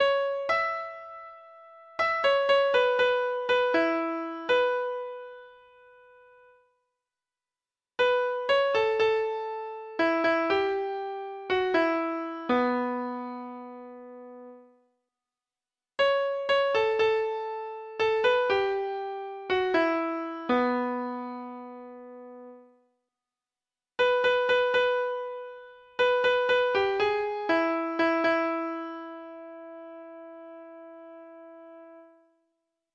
Folk Songs from 'Digital Tradition' Letter O One Dime Blues
Free Sheet music for Treble Clef Instrument
Traditional (View more Traditional Treble Clef Instrument Music)
blues